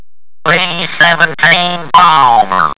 b-17bomber.wav